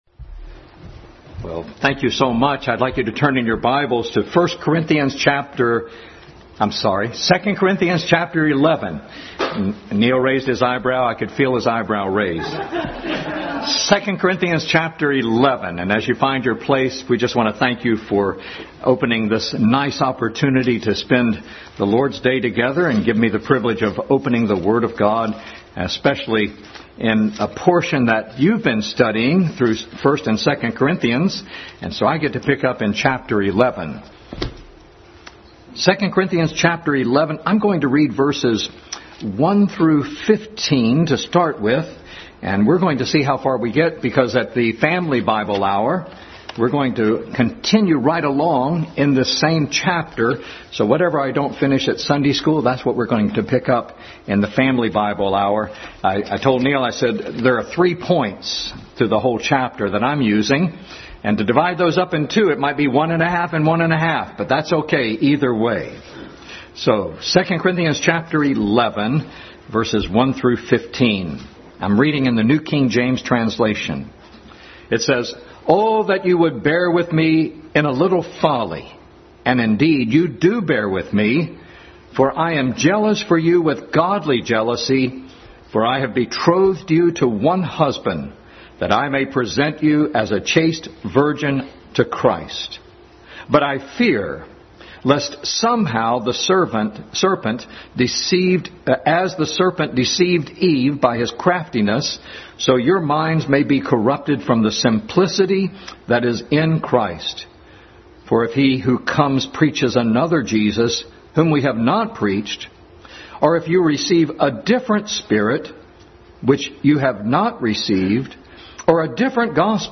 Adult Sunday School Class continued study in 2 Corinthians.
2 Corinthians 11:1-15 Service Type: Sunday School Adult Sunday School Class continued study in 2 Corinthians.